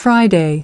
23. Friday /ˈfraɪ.deɪ/: thứ 6